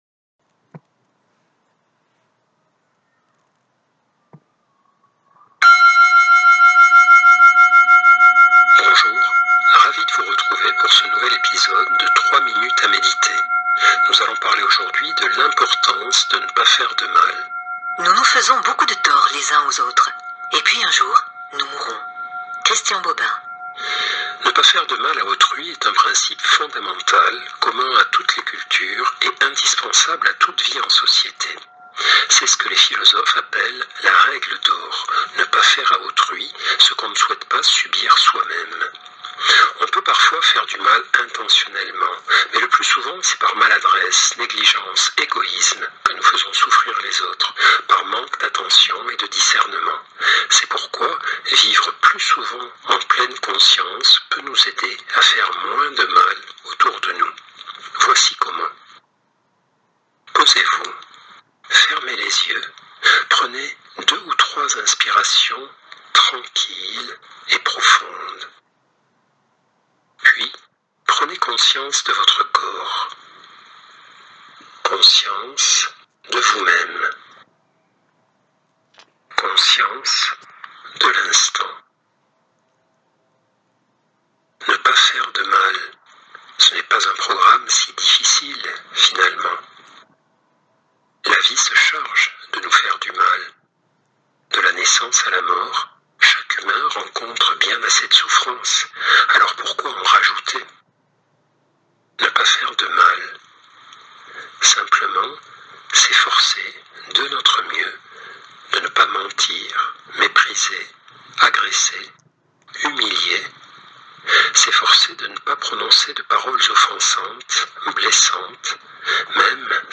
La non violence est une force d’âme et Christophe André en parle merveilleusement bien, dans l’une de ses délicieuses 3 minutes à méditer, si joliment écrites et si joliment dites.